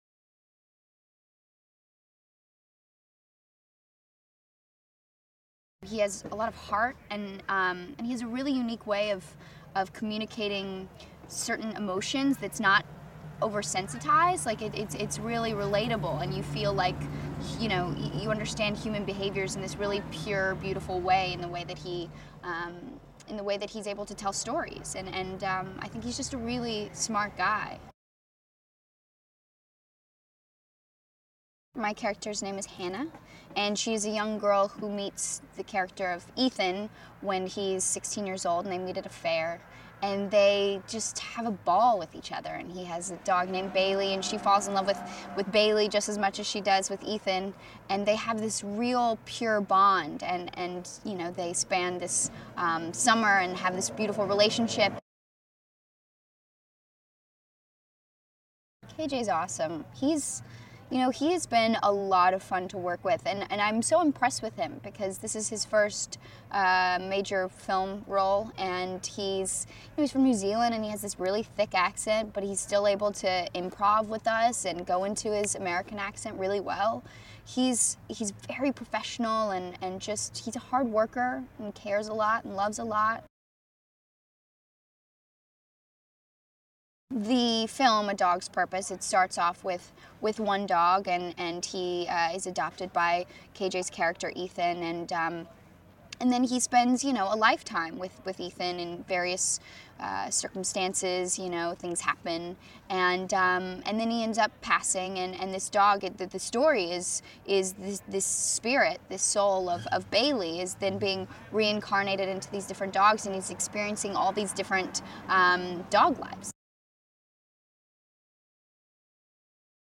Bailey_EPK_IV_BrittRobertson.mp3